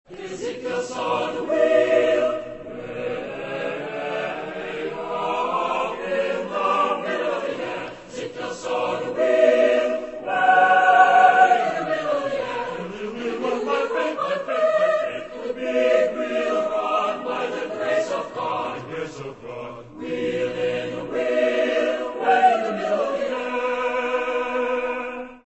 SSAATTBB (8 voix mixtes) ; Partition avec réduction clavier pour répétition.
Spiritual Afro-Américain.
Solistes : Soprano (1) / Bass (1) (2 soliste(s))
Tonalité : sol bémol majeur